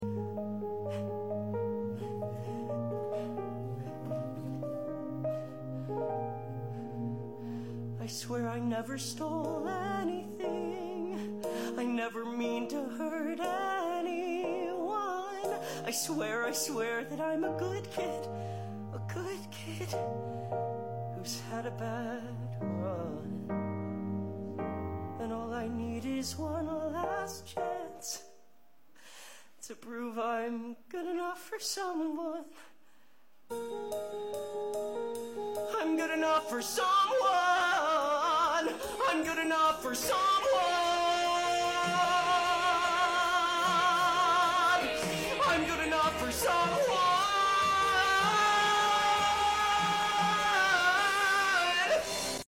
A 70-minute, one-act adaptation of the Broadway hit!